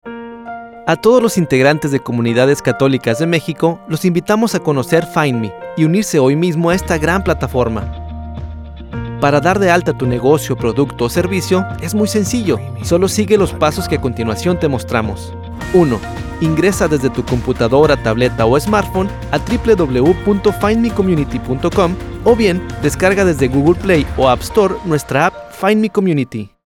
Spanish voice Natural Conversational
Sprechprobe: Industrie (Muttersprache):